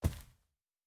Footstep Carpet Walking 1_07.wav